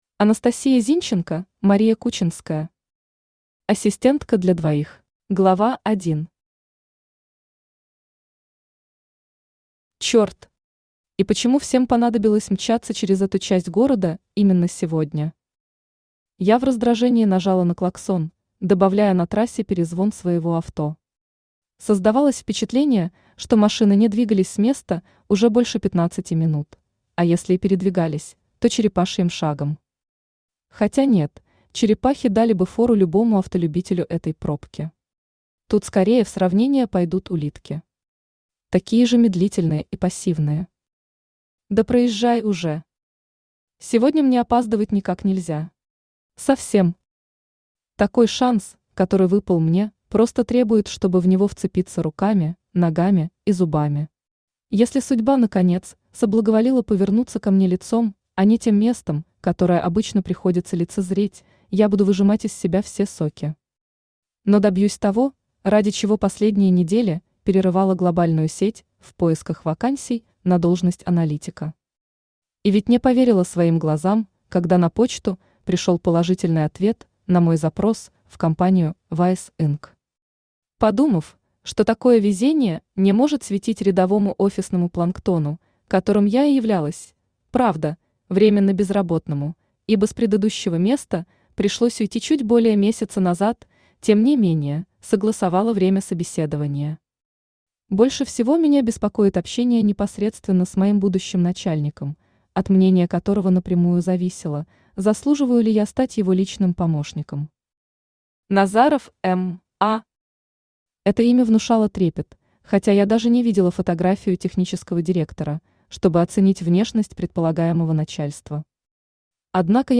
Aудиокнига Ассистентка для двоих Автор Анастасия Зинченко Читает аудиокнигу Авточтец ЛитРес.